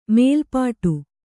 ♪ mēlpāṭu